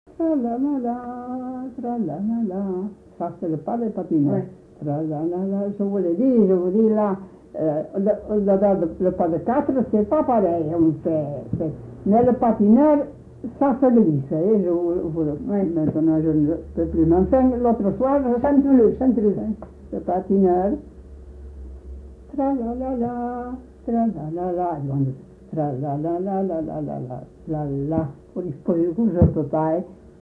Aire culturelle : Marmandais gascon
Lieu : Casteljaloux
Genre : chant
Effectif : 1
Type de voix : voix de femme
Production du son : fredonné
Danse : pas des patineurs